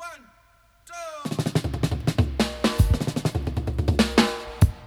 136-FILL-FX.wav